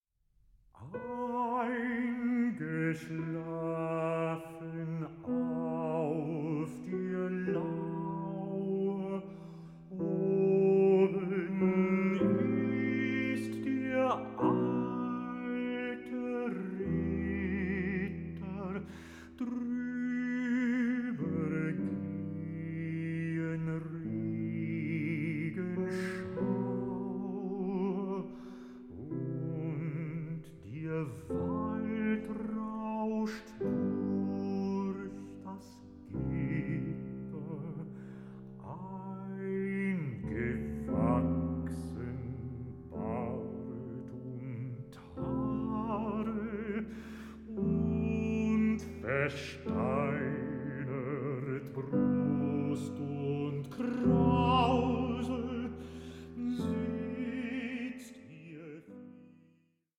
192/24 Stereo  18,99 Select